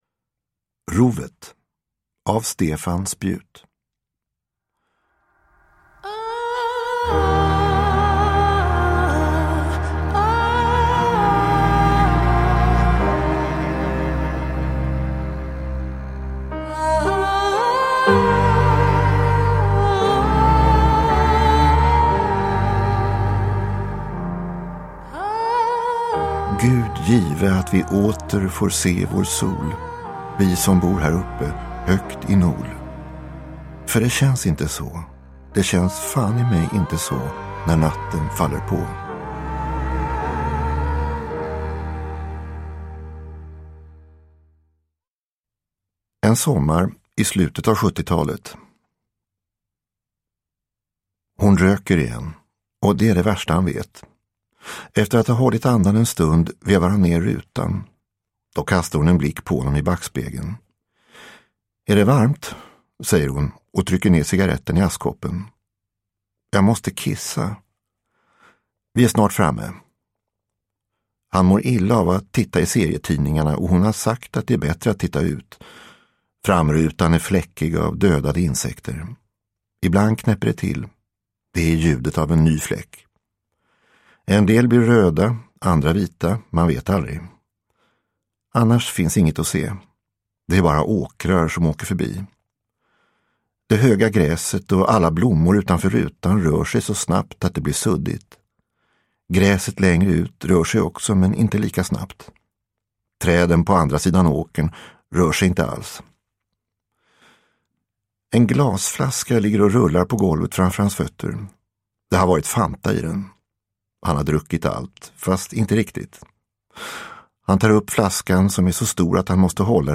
Rovet – Ljudbok – Laddas ner